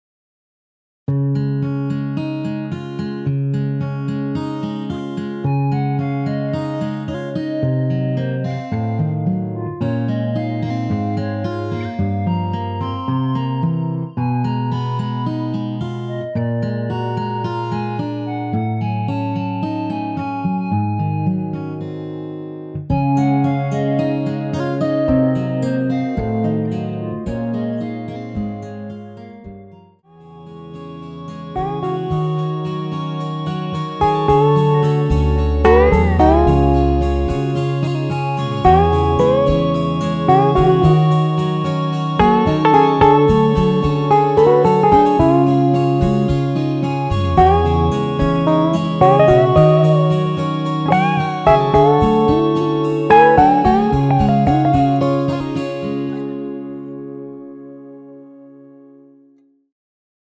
멜로디 MR입니다.
엔딩이 페이드 아웃이라 마지막 가사 “영영”을 4번 하고 엔딩을 만들었습니다.(미리듣기 참조)
원키에서(+9)올린 멜로디 포함된 MR입니다.
Db
앞부분30초, 뒷부분30초씩 편집해서 올려 드리고 있습니다.